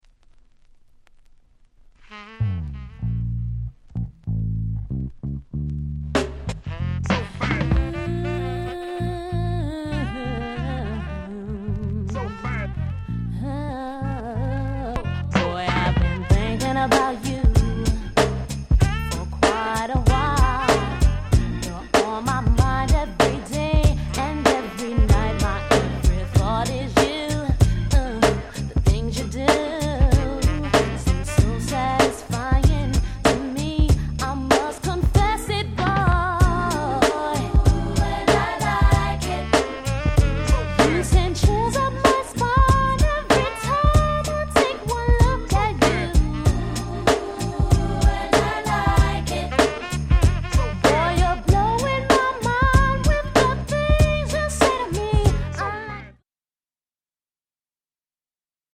問答無用の90's R&B Classic !!